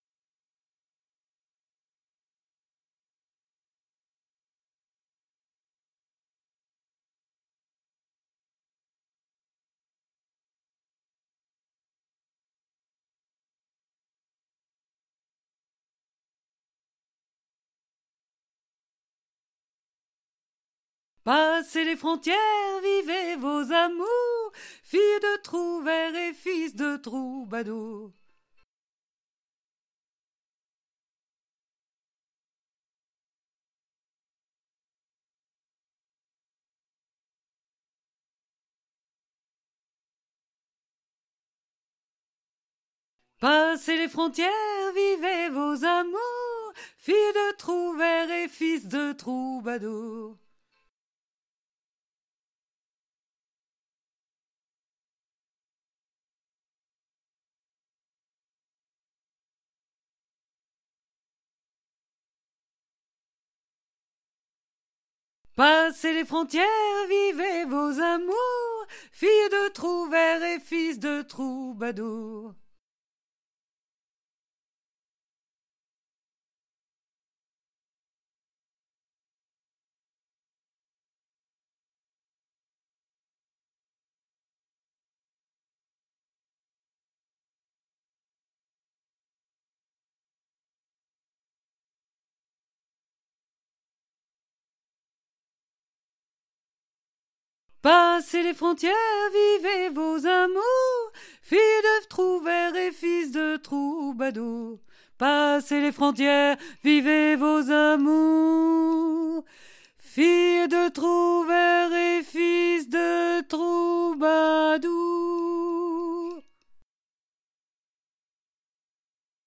GUITARE2.mp3